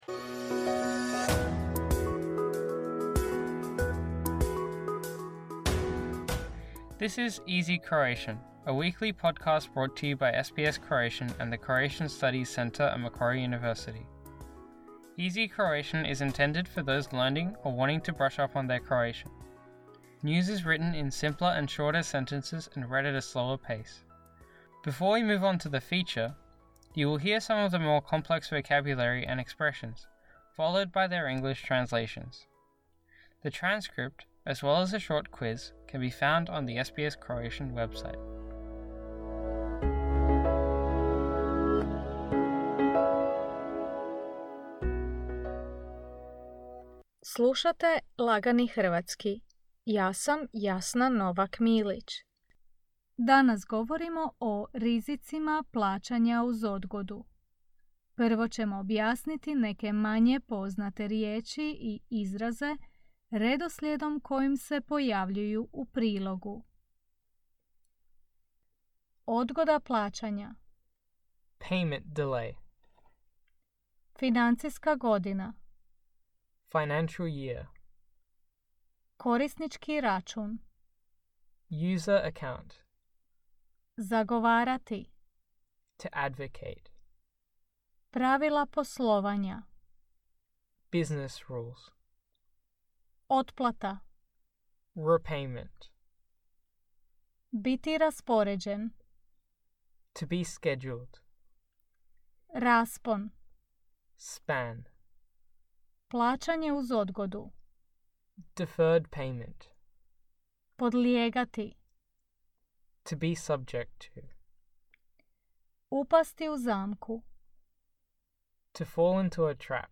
“Easy Croatian” is intended for those learning or wanting to brush up their Croatian. News is written in simpler and shorter sentences and read at a slower pace. Before we move on to the feature, you will hear some of the more complex vocabulary and expressions, followed by their English translations.